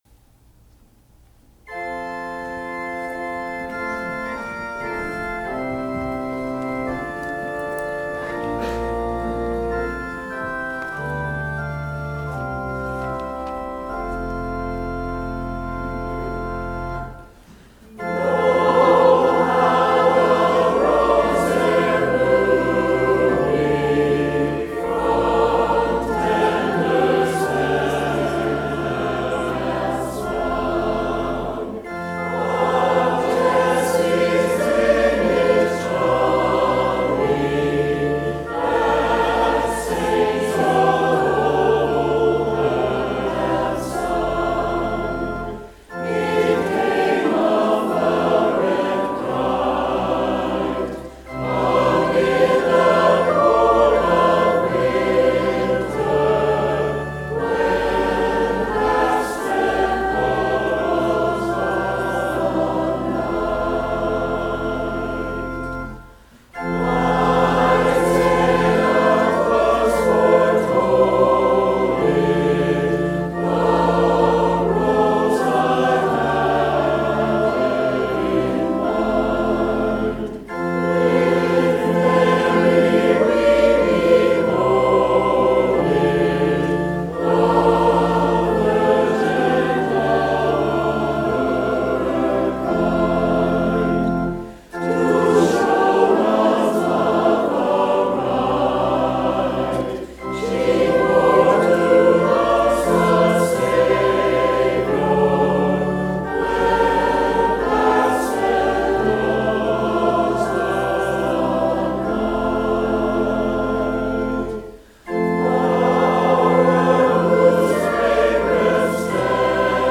Christmas Eve Service
Organ and Piano